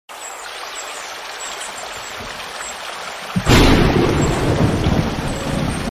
ASMR Rain In The Countryside Sound Effects Free Download